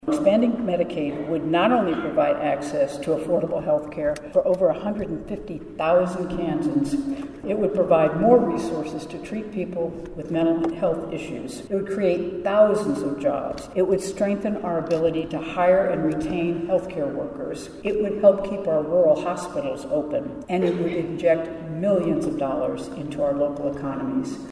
KS Gov. Kelly speaks to hundreds of health conference attendees